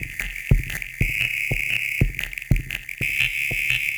Abstract Rhythm 41.wav